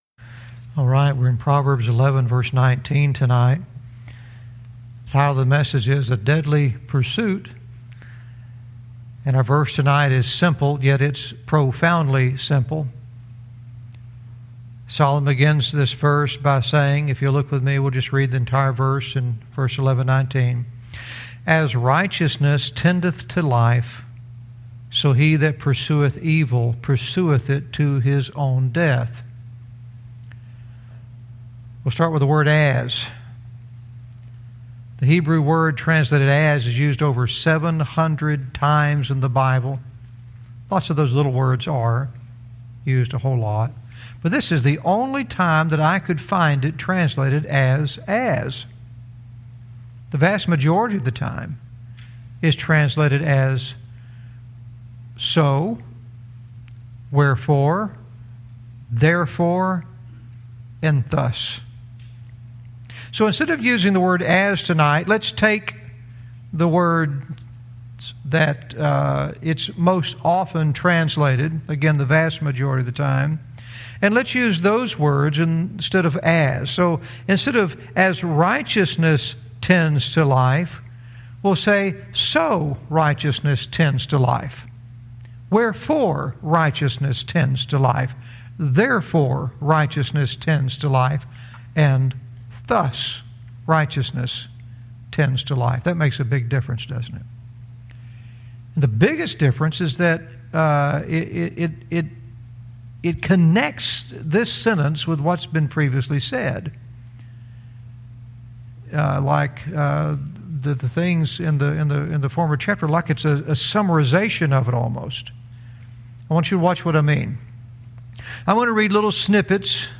Verse by verse teaching - Proverbs 11:19 "A Deadly Pursuit"